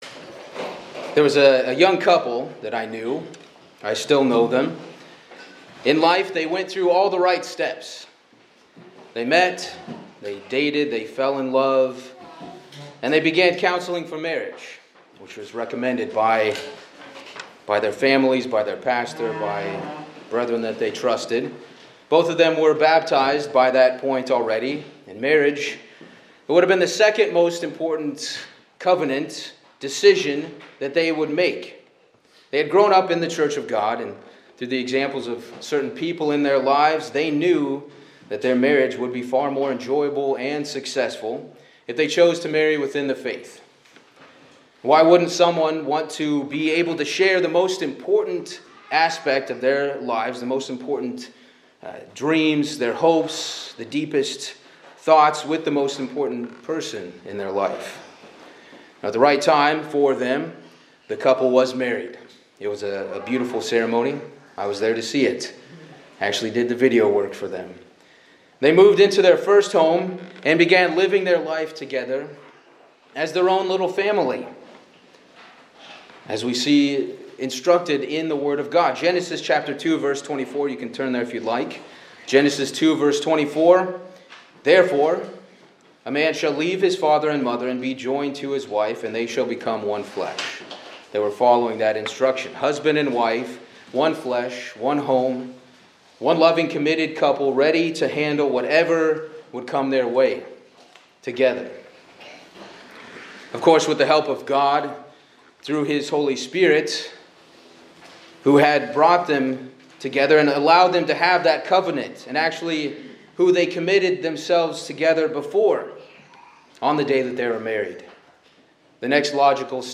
This sermon explores the Christian doctrine of the resurrections, beginning with a personal story of a couple’s loss, highlighting the pain of death and the hope found in God’s promises.
Given in Hartford, CT